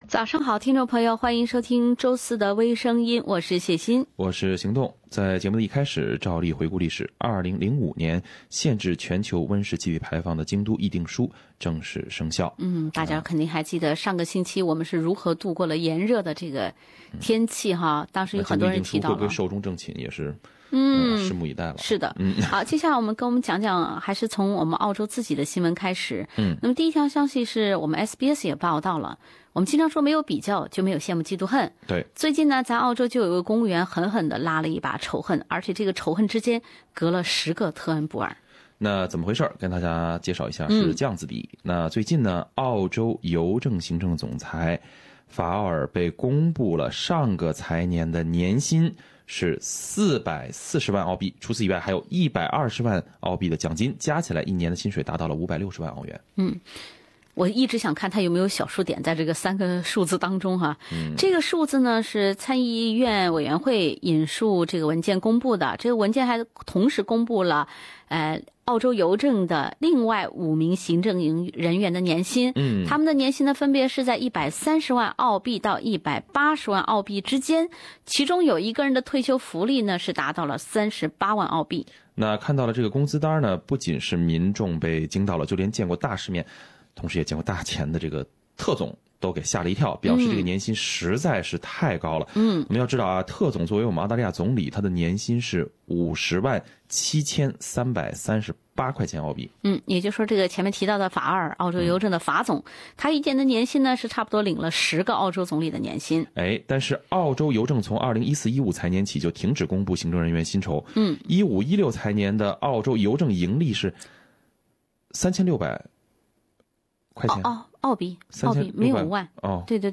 另类轻松的播报方式，深入浅出的辛辣点评；包罗万象的最新资讯；倾听全球微声音。